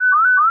alarm_siren_loop_03.wav